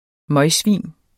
Udtale [ ˈmʌjˌsviˀn ]